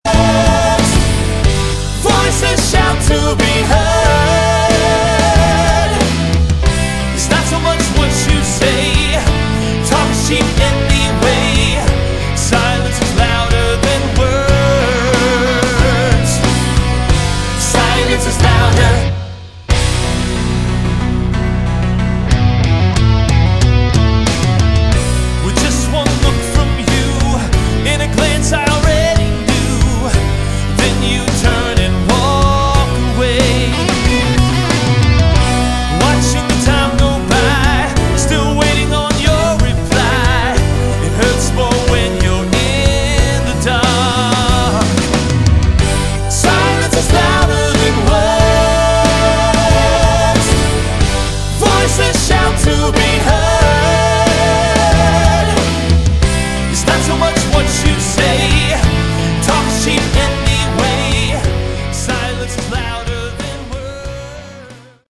Category: AOR / Melodic Rock